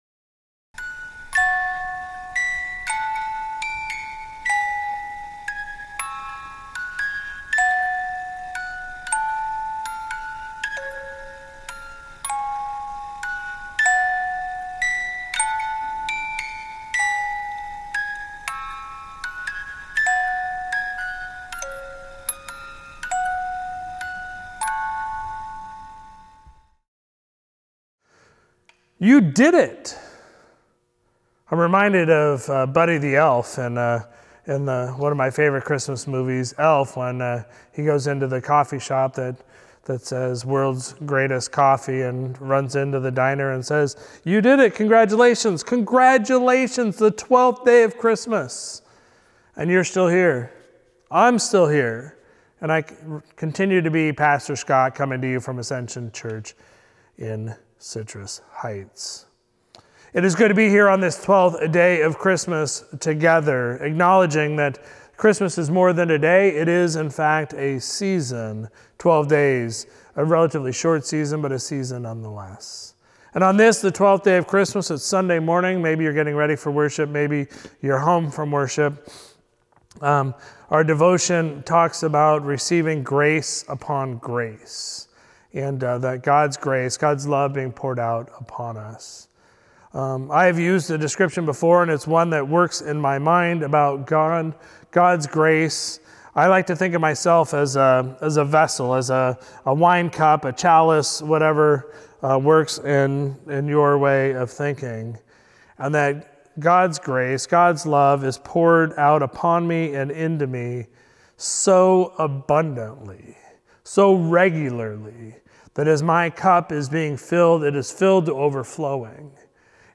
On each of the Twelve Days of Christmas (December 25 to January 5), Ascension Lutheran Church of Citrus Heights will share a short reflection. The theme each day relates to that day’s devotion in The Mess in the Messiah, our Advent/Christmas devotional published by Pilgrim Press.